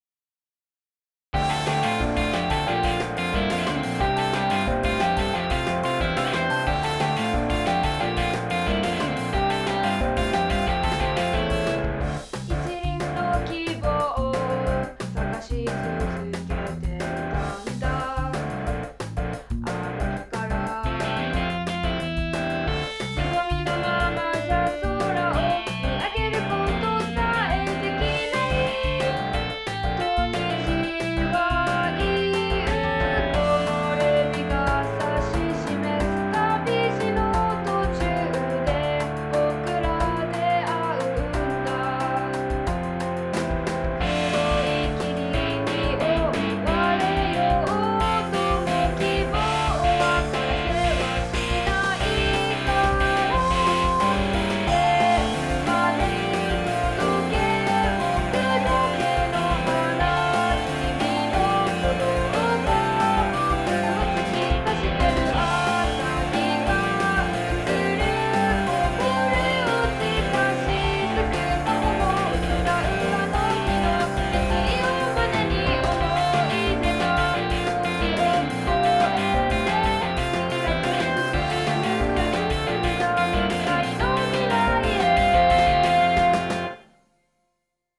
しっかりアニメOPしてて、
TVサイズver